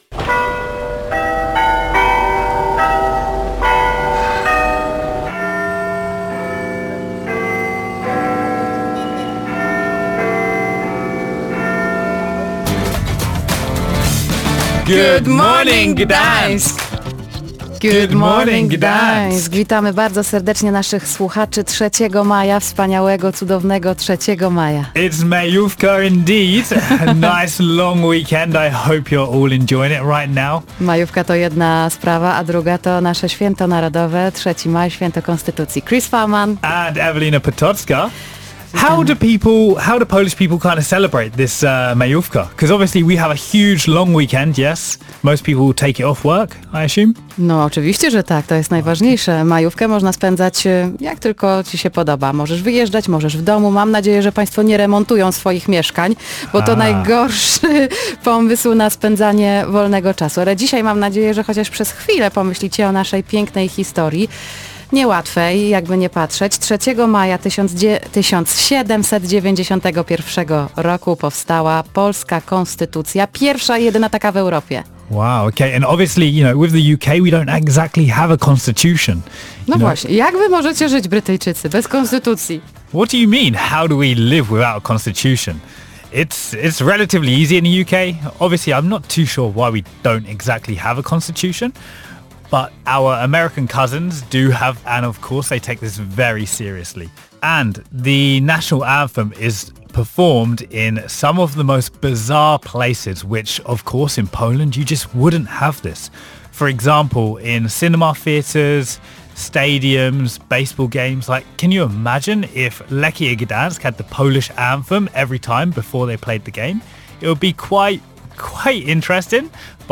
W audycji pojawił się także gość specjalny